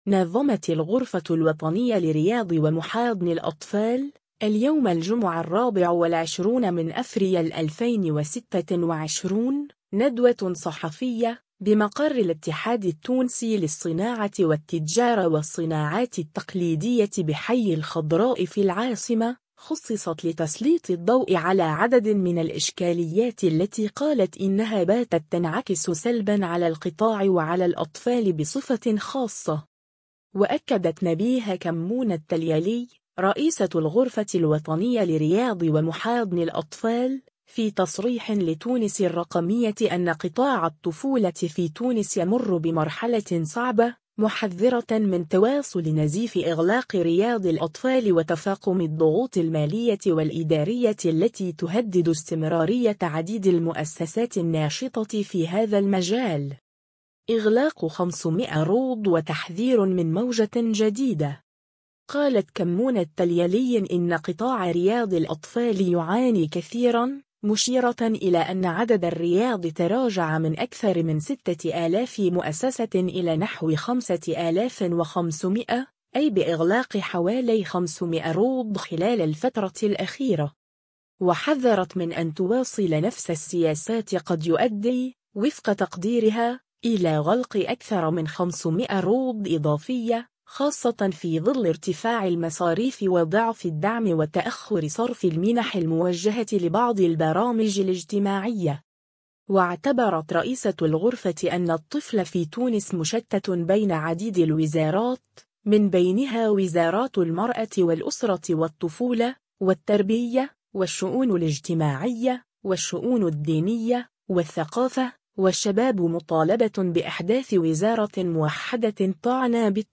نظمت الغرفة الوطنية لرياض ومحاضن الأطفال، اليوم الجمعة 24 أفريل 2026، ندوة صحفية، بمقر الاتحاد التونسي للصناعة والتجارة والصناعات التقليدية بحي الخضراء في العاصمة، خُصصت لتسليط الضوء على عدد من الإشكاليات التي قالت إنها باتت تنعكس سلبًا على القطاع وعلى الأطفال بصفة خاصة.